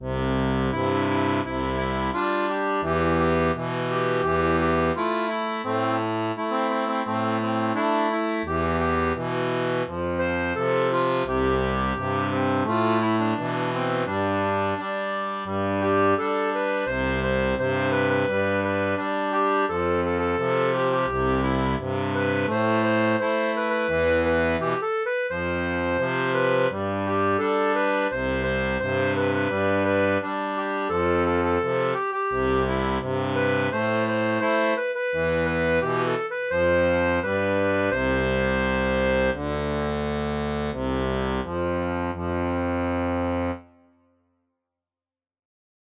• la version originale pour accordéon diatonique à 2 rangs
Gospel